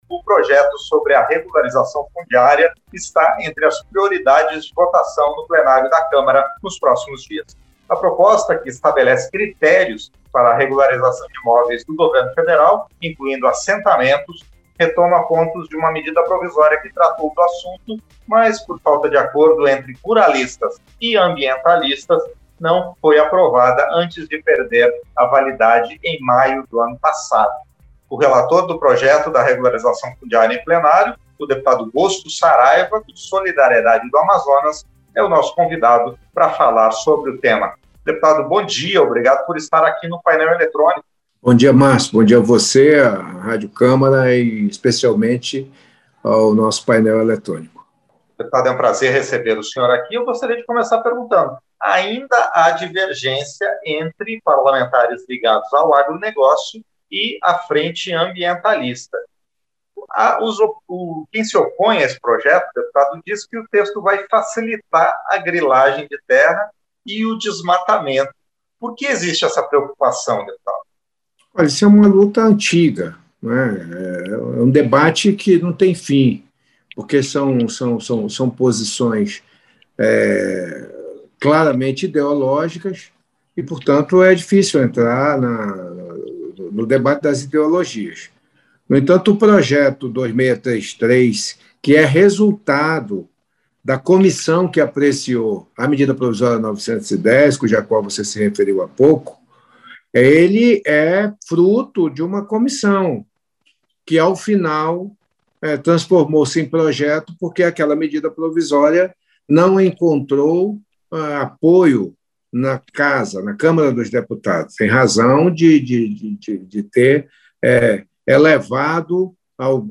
Entrevista - Dep. Bosco Saraiva (SD-AM)